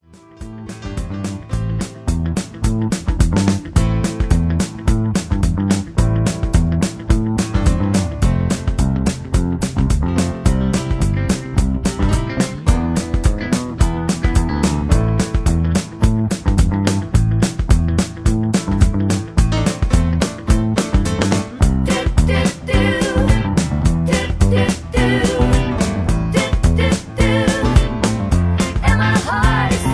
Karaoke MP3 Backing Tracks
Just Plain & Simply "GREAT MUSIC" (No Lyrics).